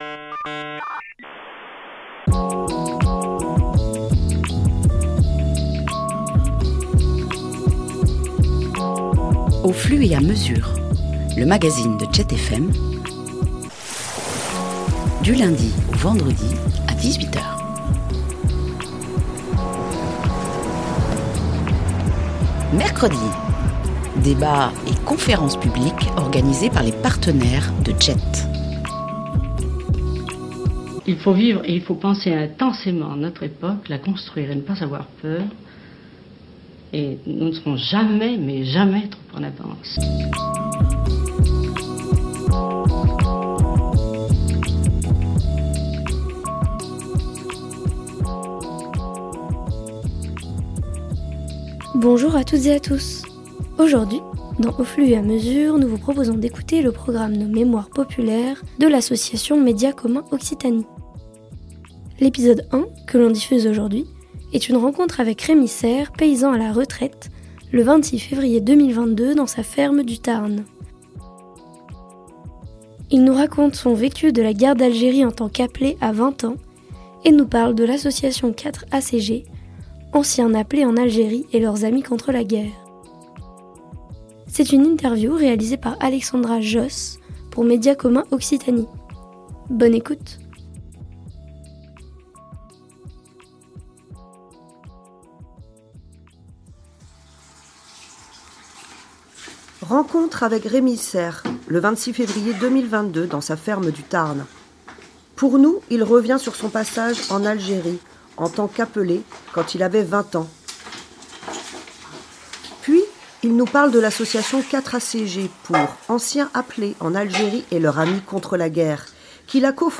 Une interview pour le programme "Nos Mémoires Populaires" de Média Commun Occitanie